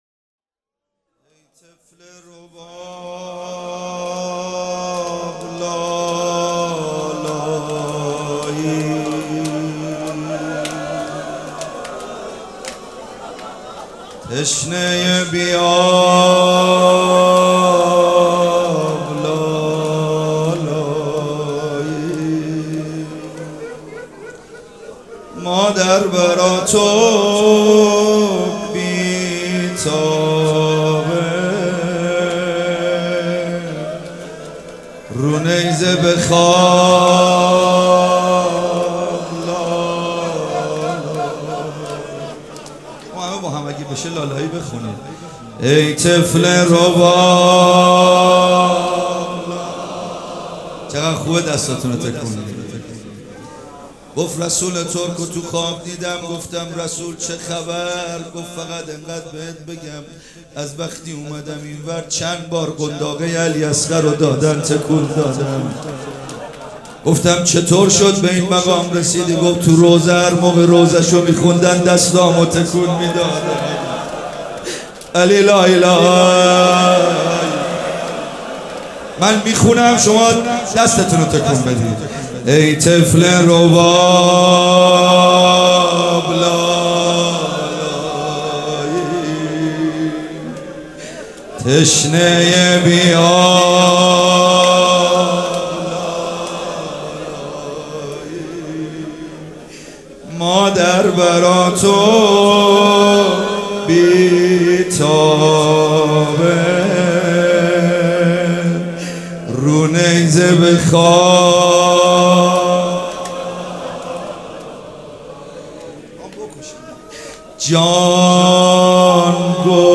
روضه
مراسم عزاداری شب دوم